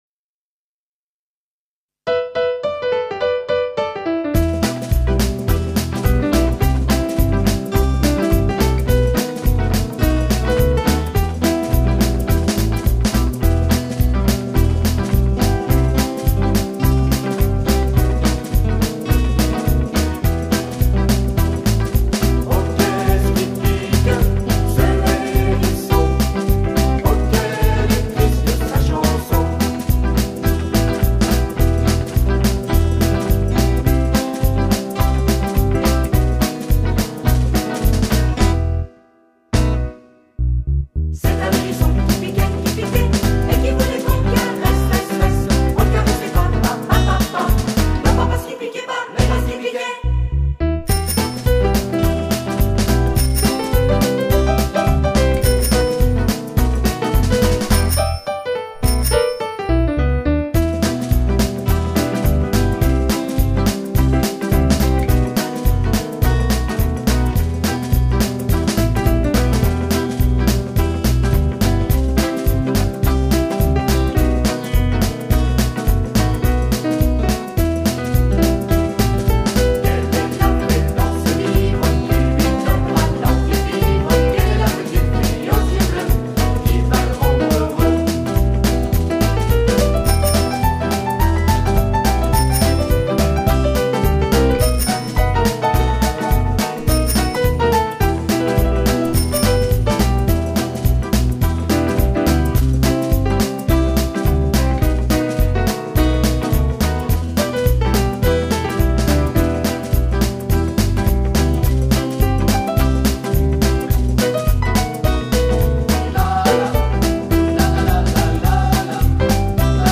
accompagnement de la chanson